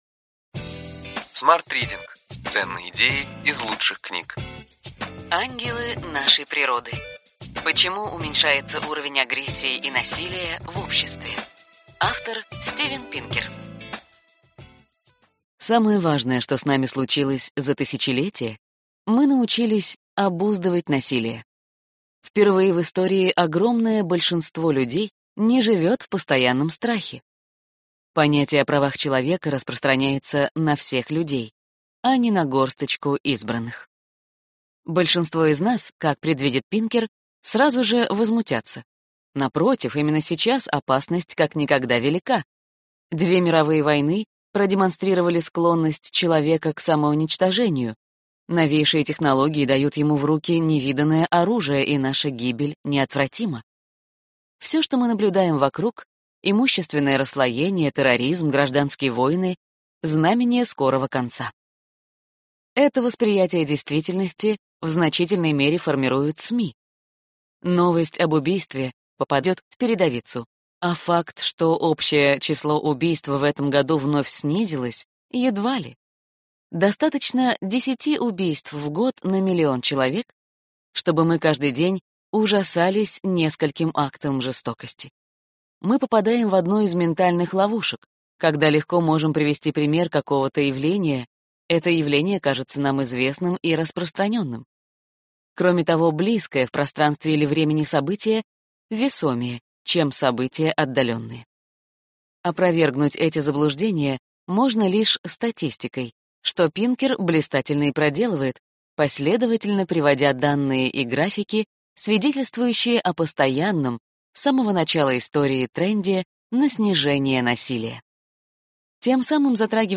Аудиокнига Год личной эффективности. Cледую большим смыслам. Экзистенциальный интеллект. Сборник 4 | Библиотека аудиокниг